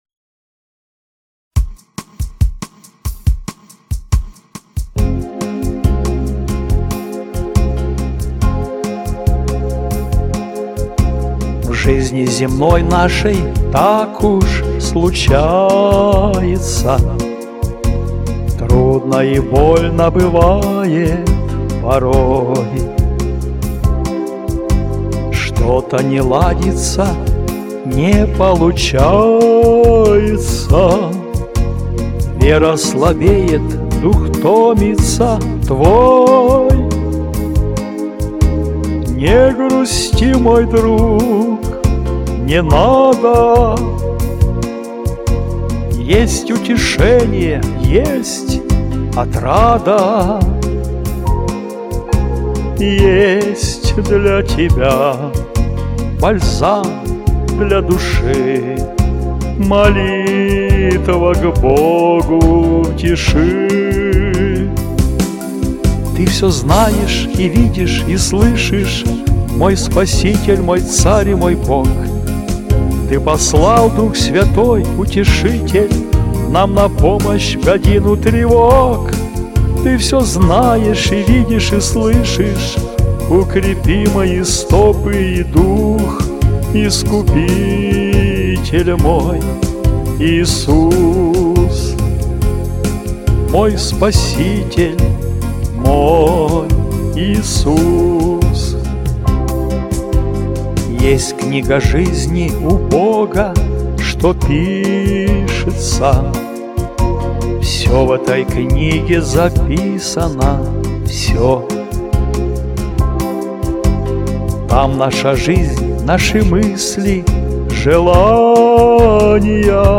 Христианские песни